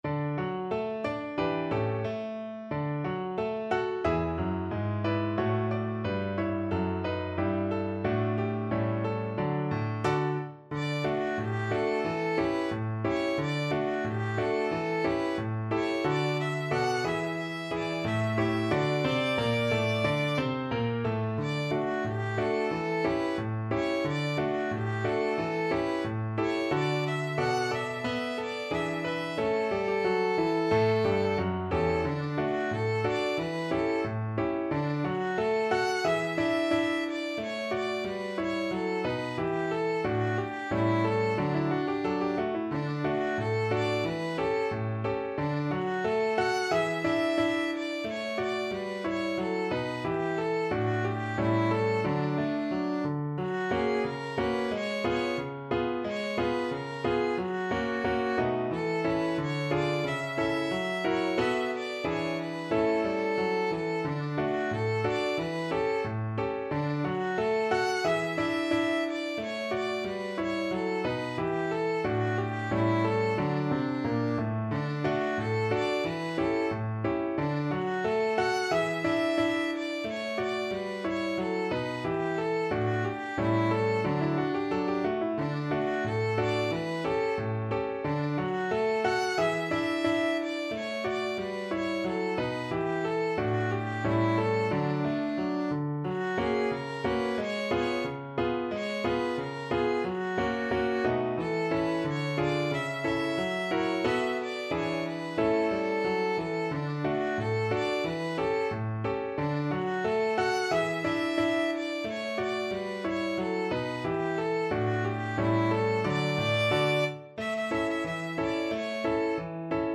2/2 (View more 2/2 Music)
=90 Fast and cheerful
Pop (View more Pop Violin Music)